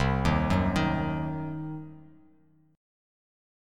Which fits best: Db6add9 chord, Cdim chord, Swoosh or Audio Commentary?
Cdim chord